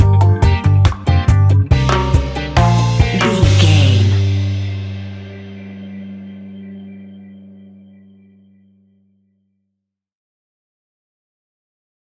Classic reggae music with that skank bounce reggae feeling.
Aeolian/Minor
WHAT’S THE TEMPO OF THE CLIP?
laid back
off beat
skank guitar
hammond organ
percussion
horns